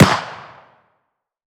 ACE3 / extras / assets / CookoffSounds / shotshell / mid_1.wav
Cookoff - Improve ammo detonation sounds